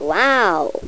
wow.mp3